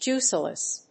アクセント・音節júice・less